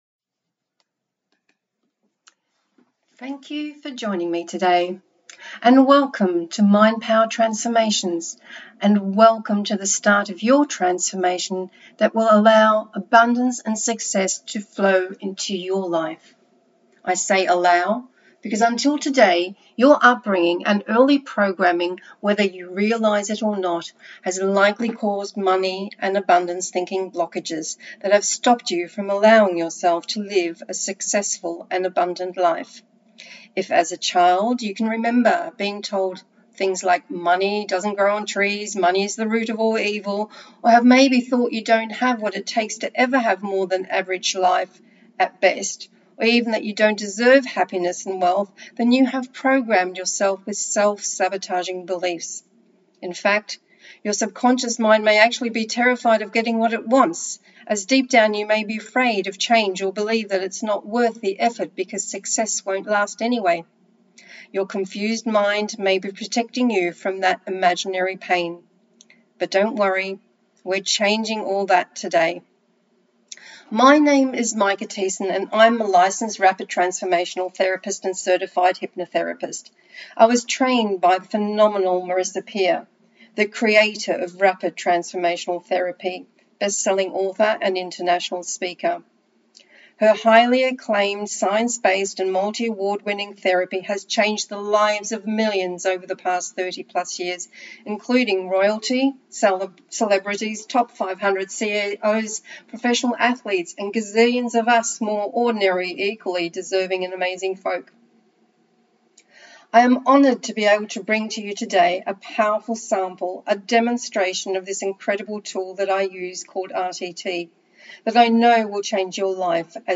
FREE Success Mindset Hypnosis Audio | Mindpower Transformations